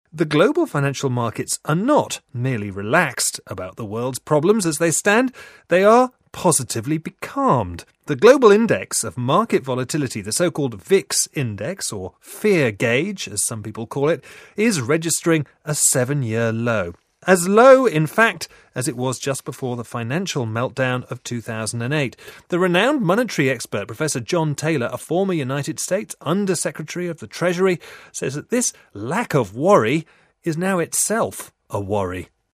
【英音模仿秀】金融市场流动性创七年新低 听力文件下载—在线英语听力室